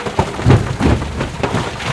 resource_idle2.wav